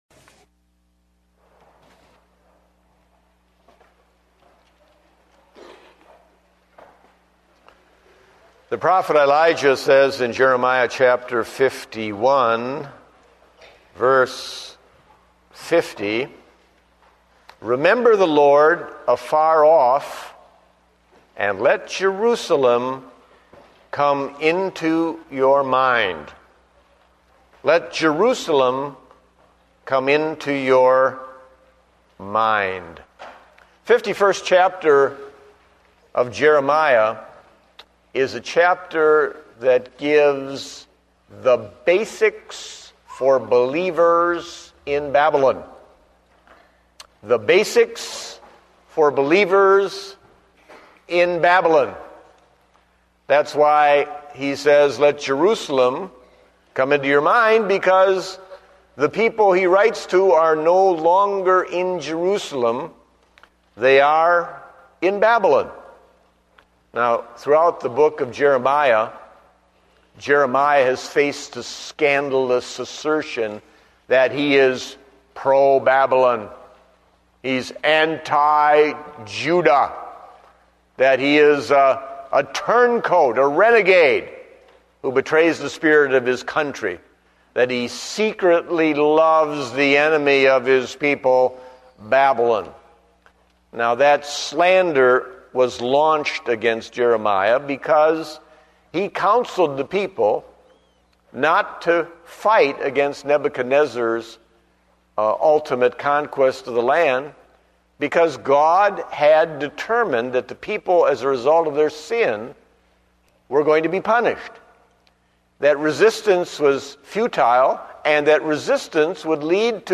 Date: November 15, 2009 (Morning Service)